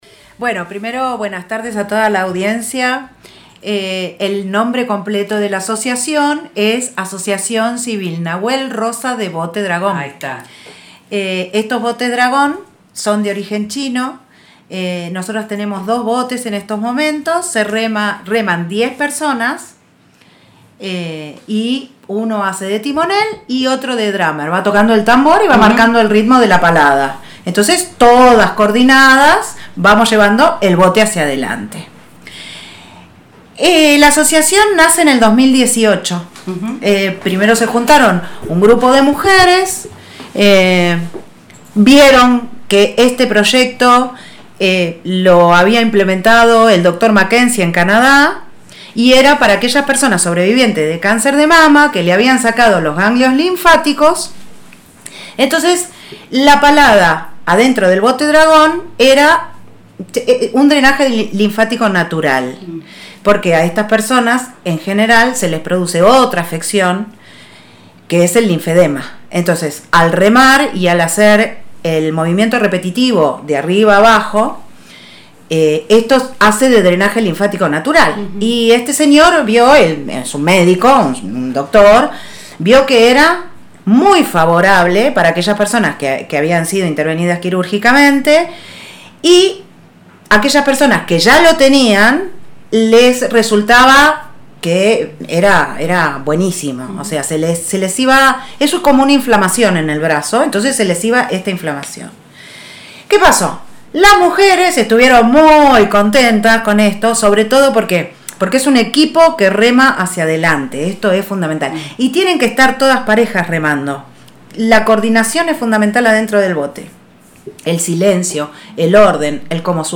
Compartimos en dos partes ese encuentro en el aire